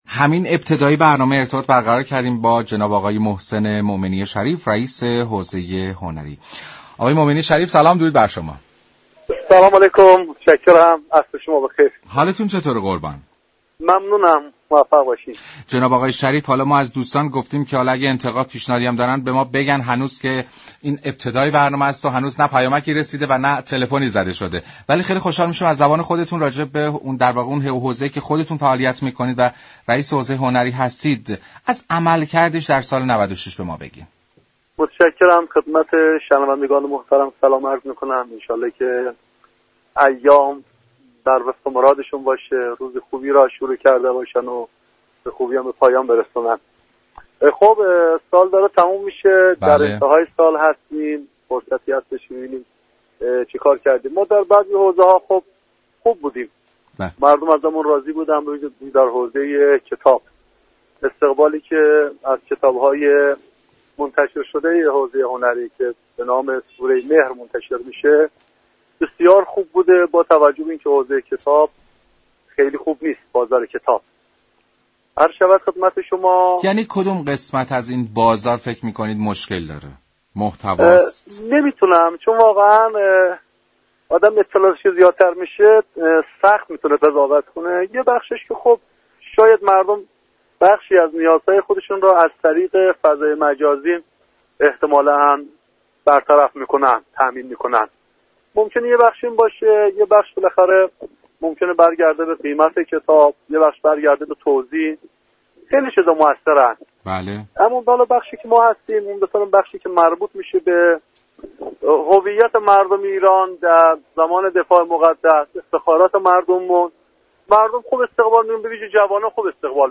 «محسن مومنی شریف» رییس حوزه هنری در گفت و گو با برنامه «كافه هنر»گفت: علی رغم همه فعالیت های انجام شده؛ اما امكانات رضایت بخش نبوده، امیدوارم سال آینده اتفاق خوبی درزمینه رخ دهد.